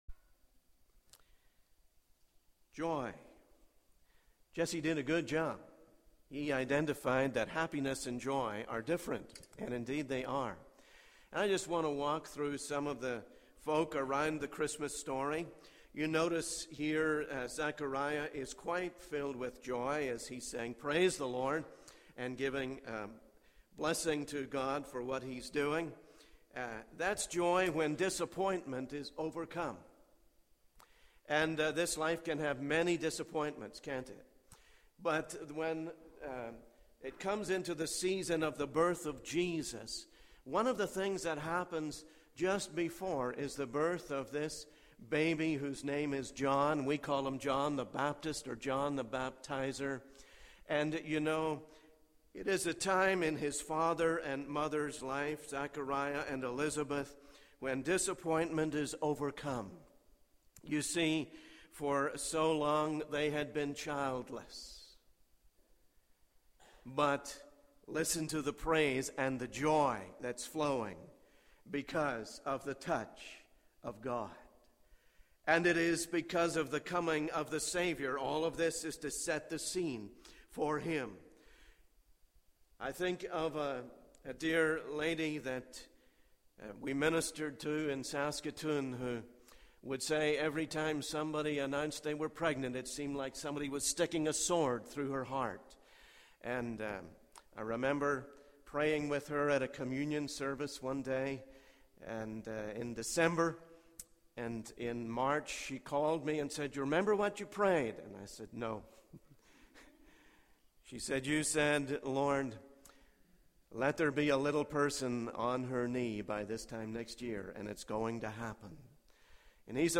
In this sermon, the speaker shares stories of individuals who experienced the joy of serving God.